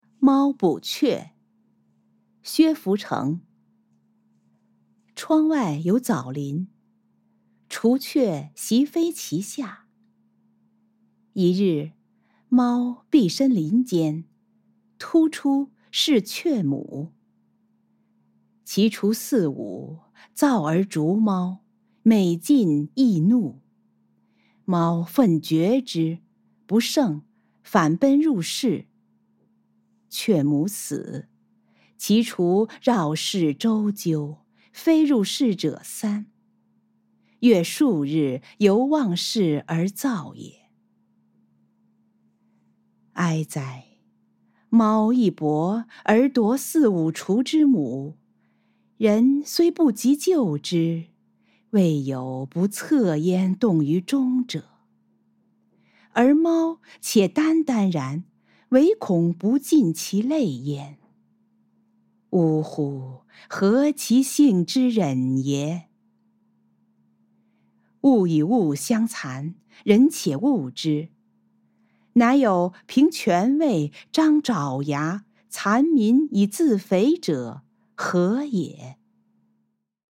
誦讀錄音